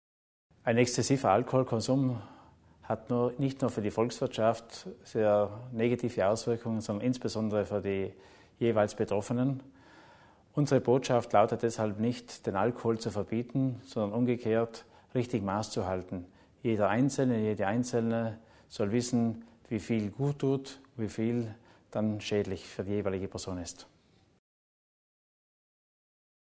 Landesrat Theiner zur Präventionskampagne